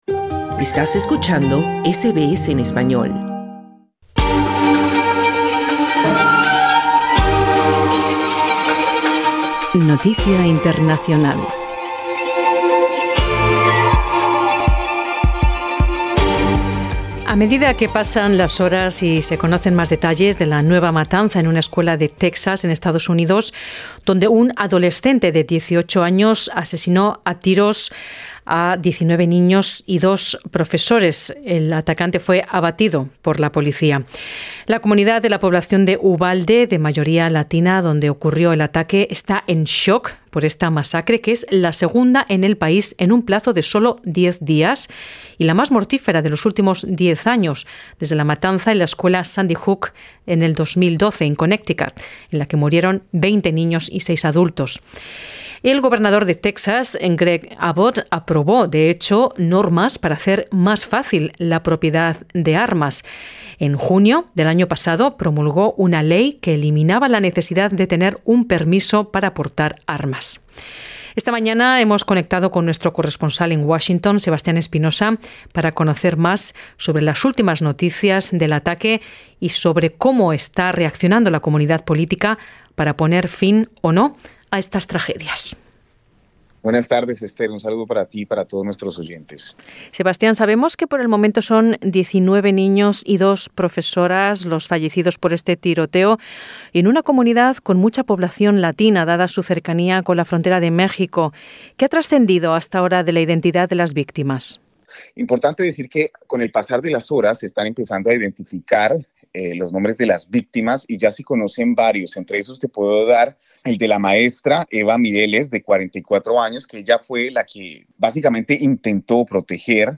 Escucha la crónica de nuestro corresponsal en Washington